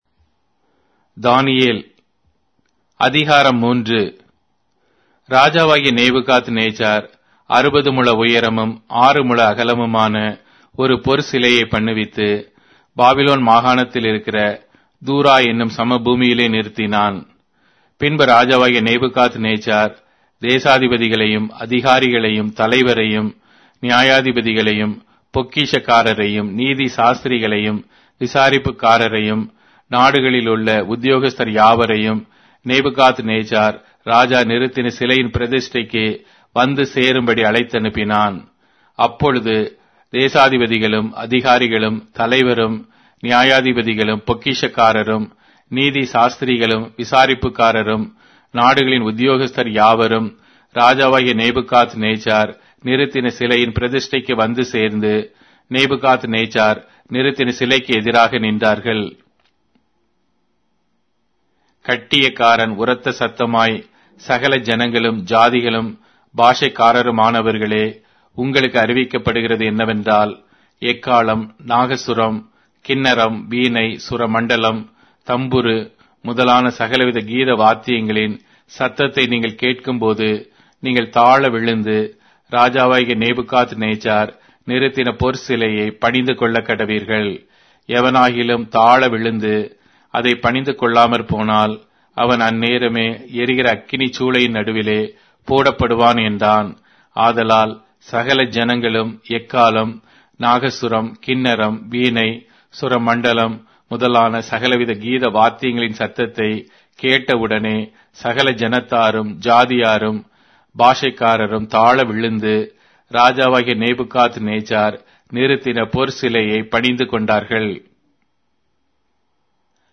Tamil Audio Bible - Daniel 7 in Irvpa bible version